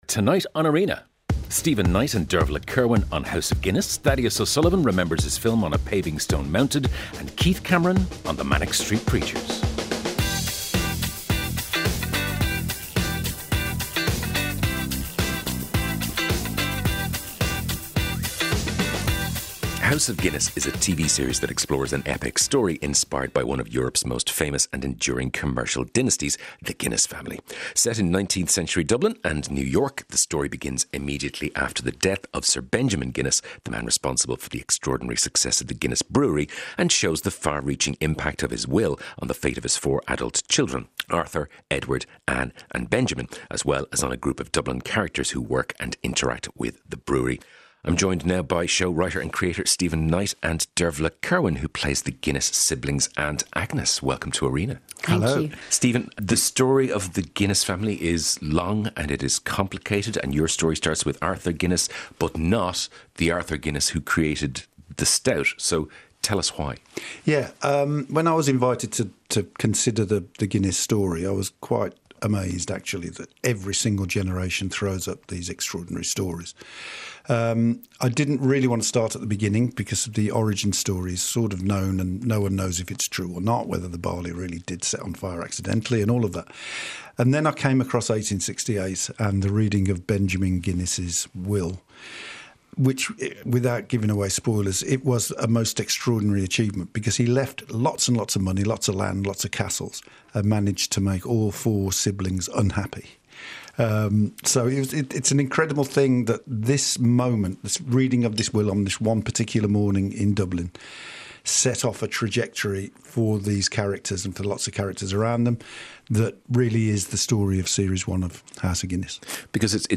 Irish podcast exploring what's happening in the world of arts, culture and entertainment. Listen live every weeknight on RTÉ Radio 1 from 7-8pm.